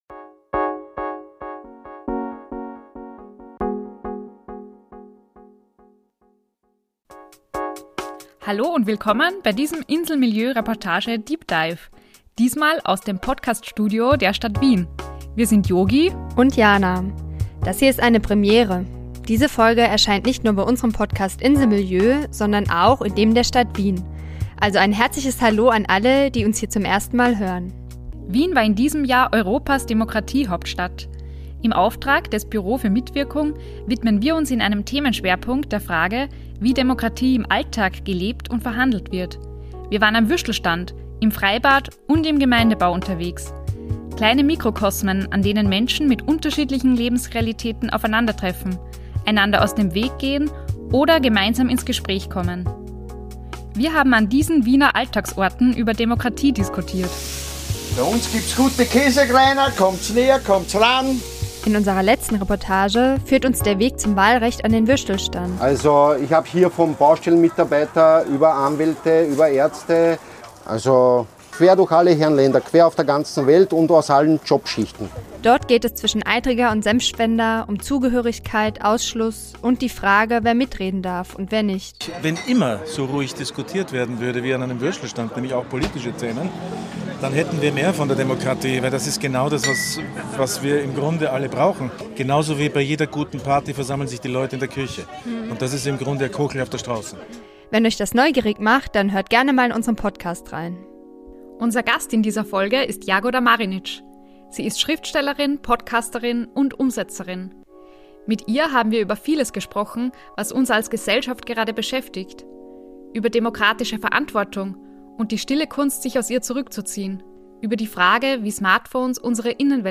Es geht um Mechanismen digitaler Plattformen, die Konflikte, Empörung und Spaltung antreiben, um die Frage, welche Rolle der Journalismus in polarisierten Zeiten spielt und um Jagoda Marinićs Idee der Lösungslust als Weg vom Denken ins Tun. Ausschnitte aus ihrer kraftvollen Rede zum Abschluss des Wiener Demokratiejahres sind Teil des Gesprächs.